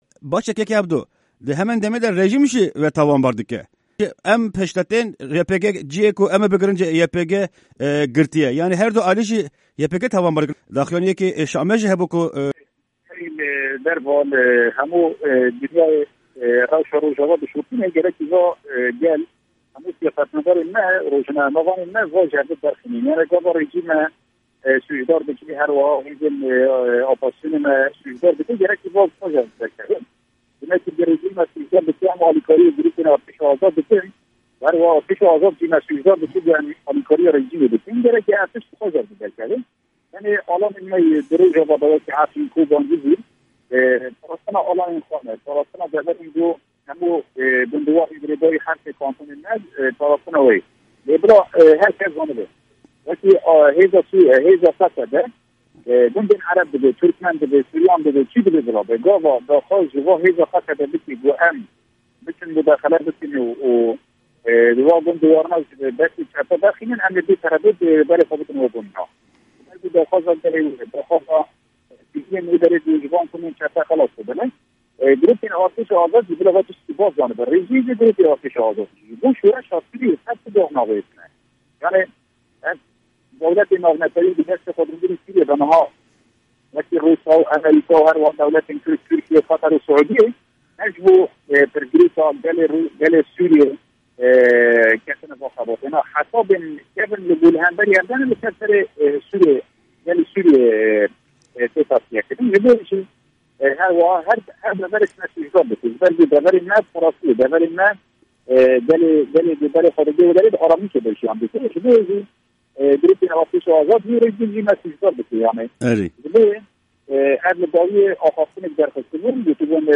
Serokê Desteya Parastinê yê Kantona Efrînê Ebdo di vê hevpeyvîna taybet de rizgarkirina firîngeha Minix û rewşa dawî li derdora Efrînê şîrove dike